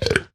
chestclosed.ogg